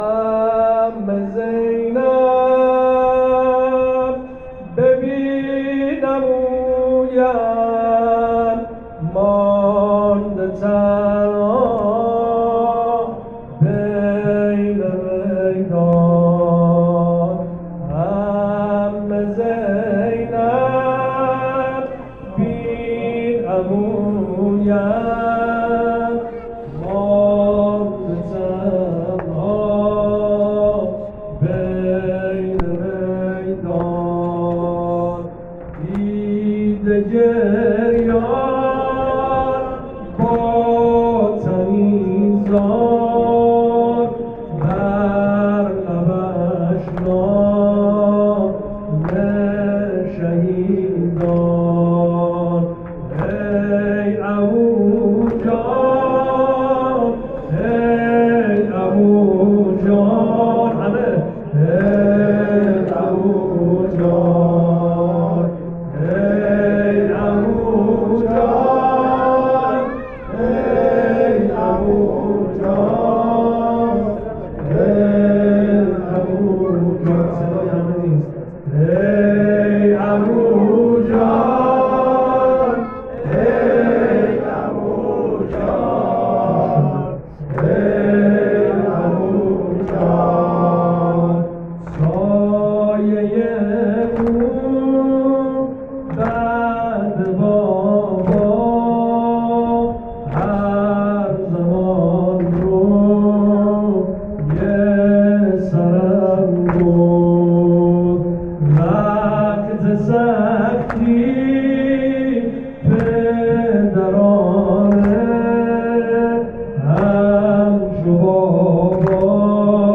شب پنجم محرم
مداحی